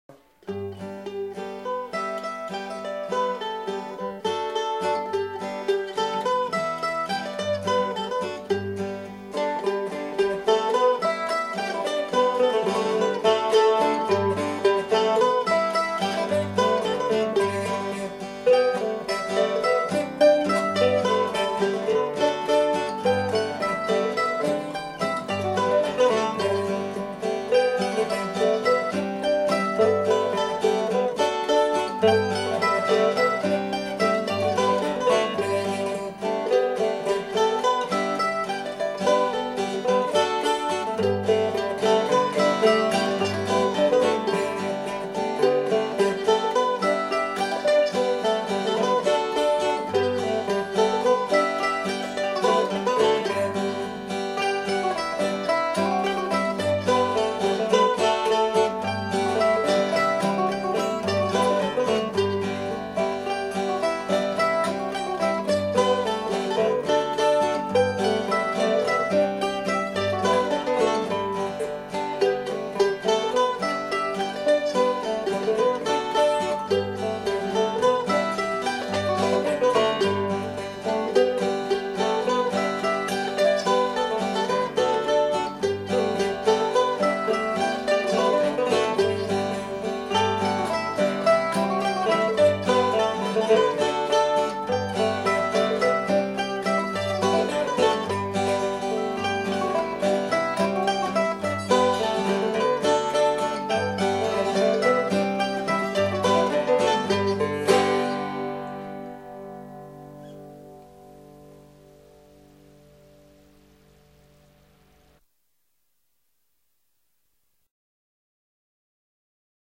Recorded at Flying Fiddle Studio
Harp
Mandolin
Guitar